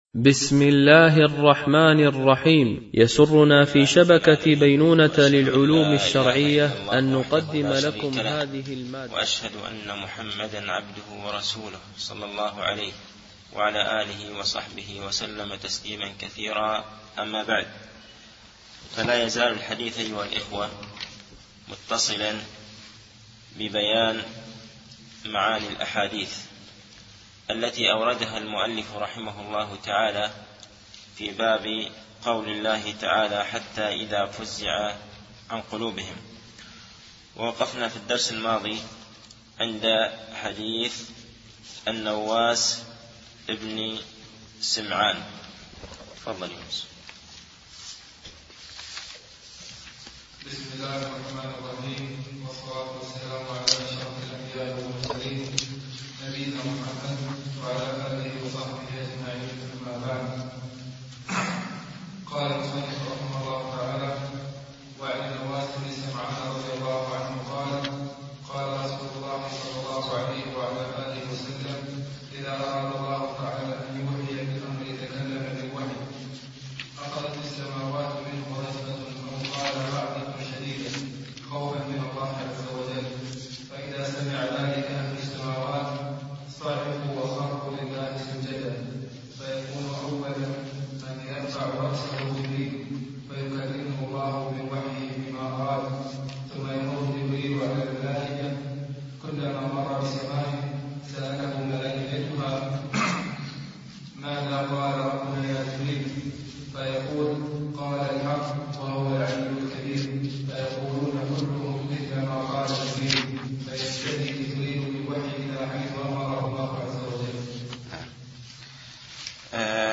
التعليق على القول المفيد على كتاب التوحيد ـ الدرس الثاني و الاربعون